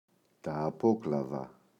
απόκλαδα, τα [a’poklaða]